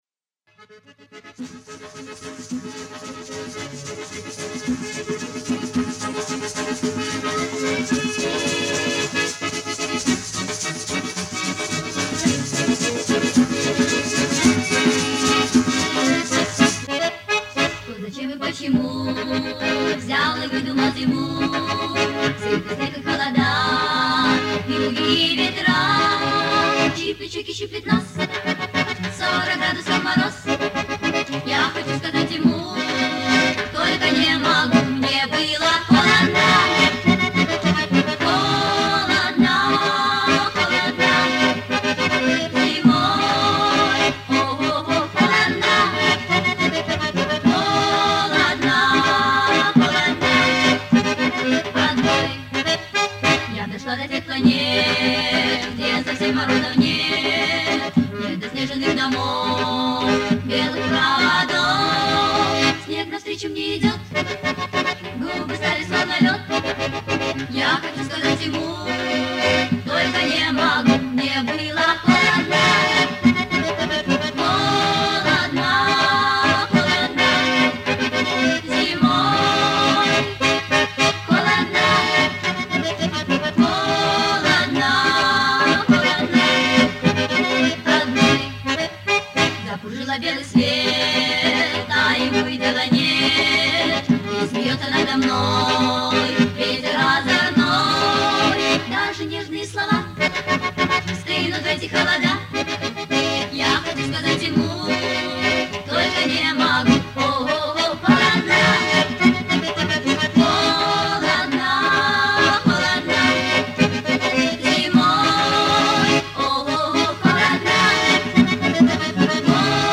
скорость оригинала